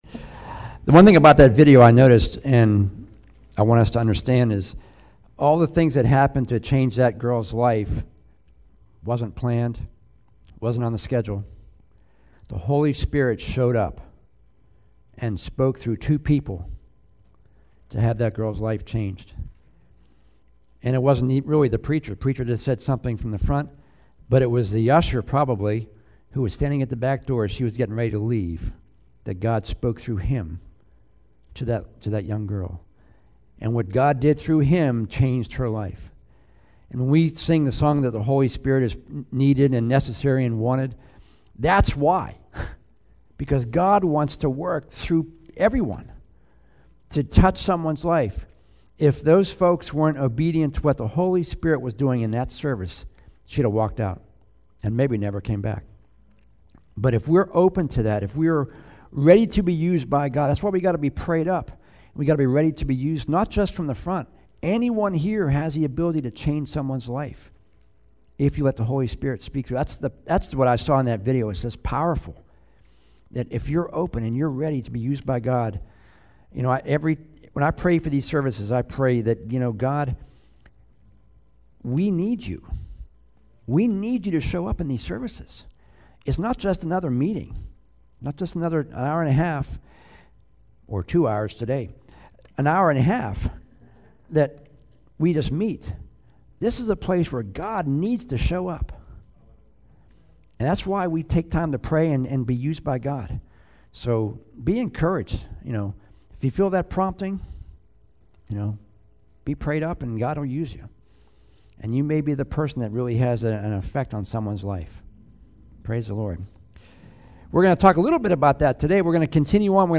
Today's sermon continued talking about the account of Jesus raising Lazarus from the dead.